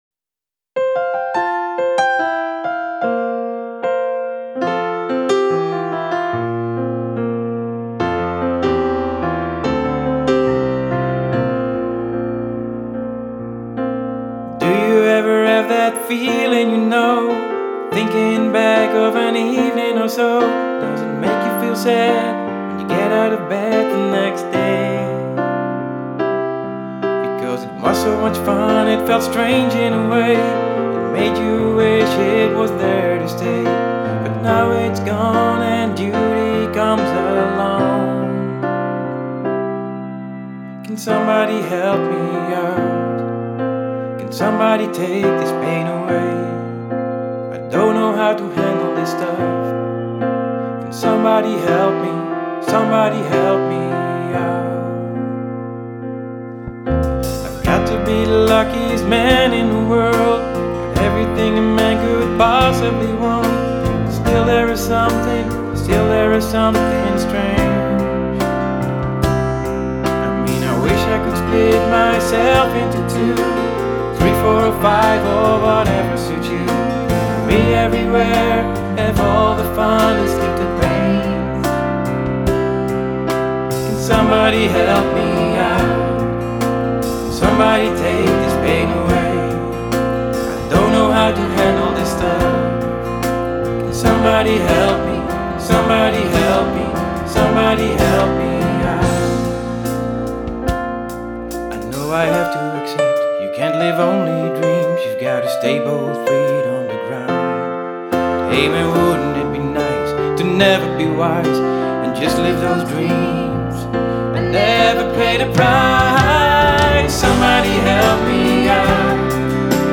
Drums
Basgitaar